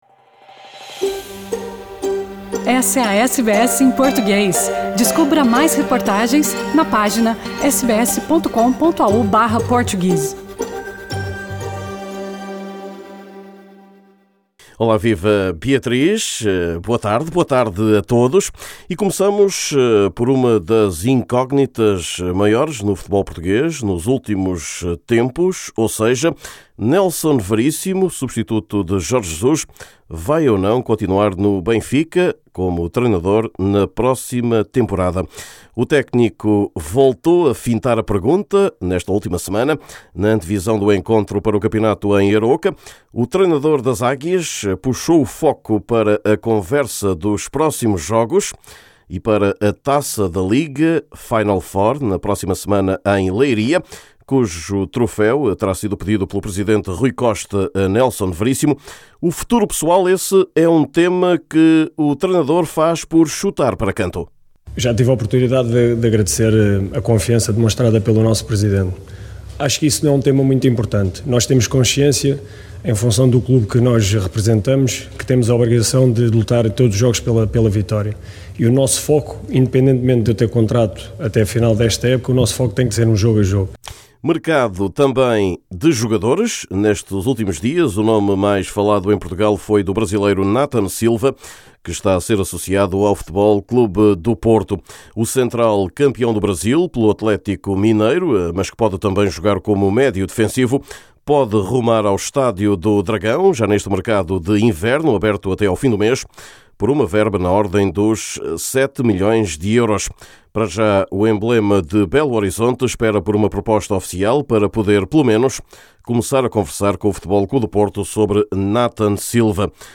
Neste boletim semanal há espaço igualmente para o mercado de jogadores. Nathan Silva, defesa central do campeão brasileiro, está a ser fortemente associado ao FC Porto.